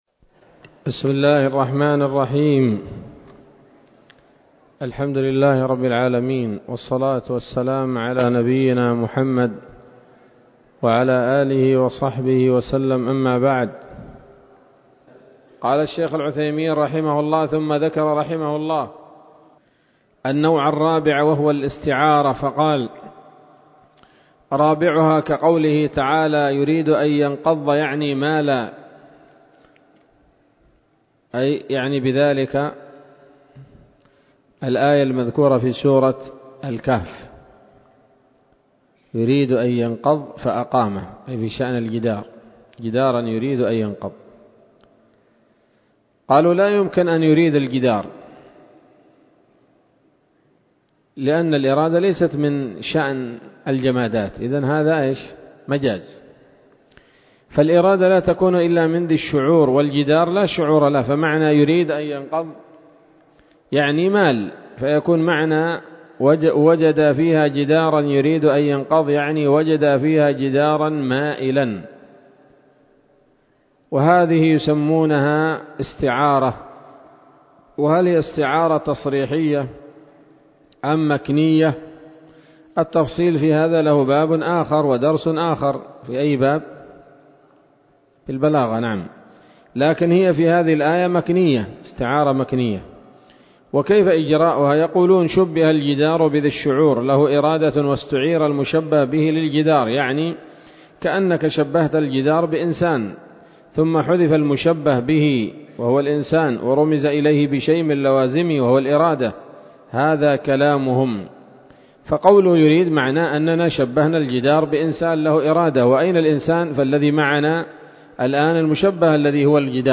الدرس الحادي والثلاثون من شرح نظم الورقات للعلامة العثيمين رحمه الله تعالى